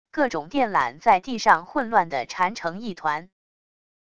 各种电缆在地上混乱地缠成一团wav音频